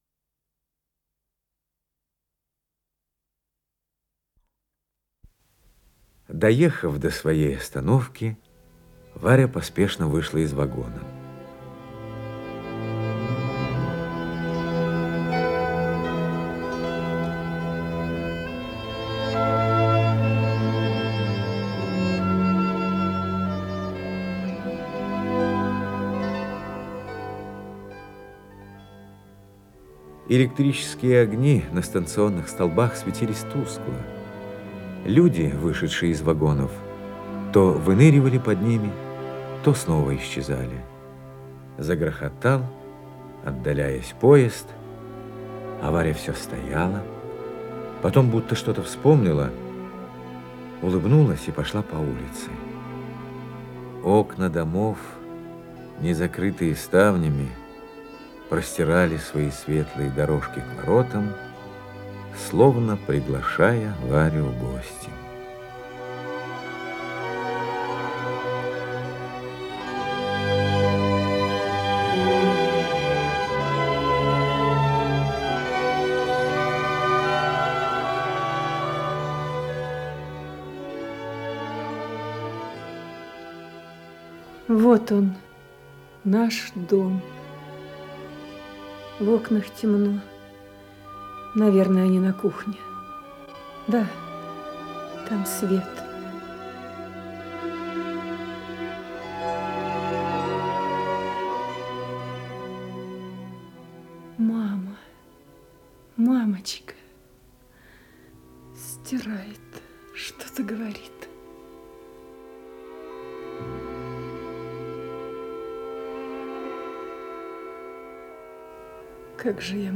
Исполнитель: Игорь Ледогоров - ведущий Зинаида Кириенко Игорь Ледогоров Леонид Куравлев Нина Сазонова
Инсценированные страницы повести